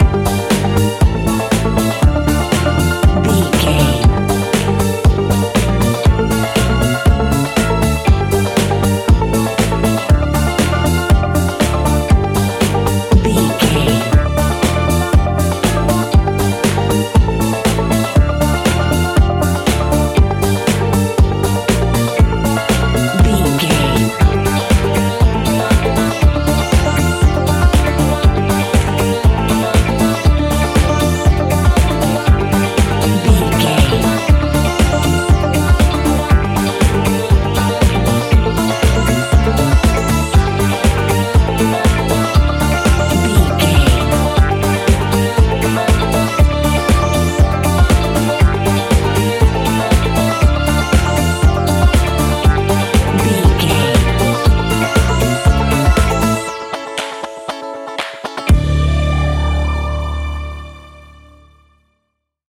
Ionian/Major
D
house
electro dance
synths
techno
trance
instrumentals